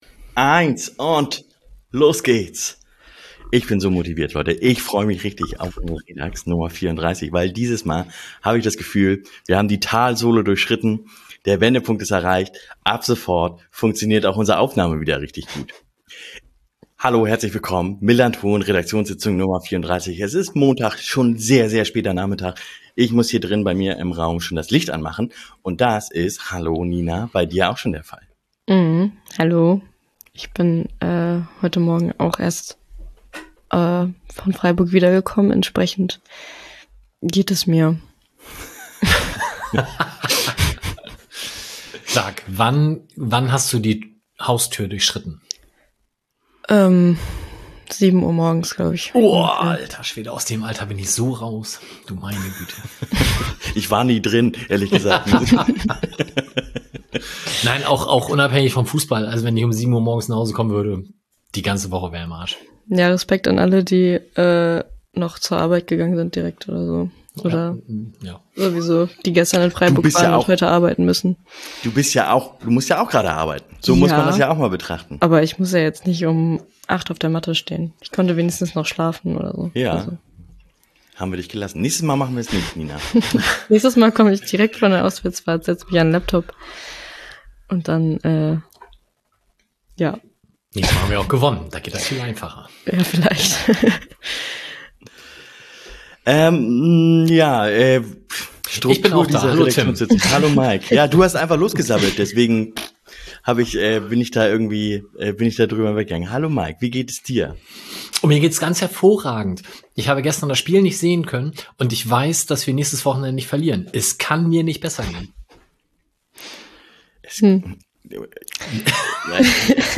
Wenn die Technik nicht klappt, ne?
aufgenommen in den Fanräumen des Millerntor-Stadions